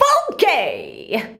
FUNKY.wav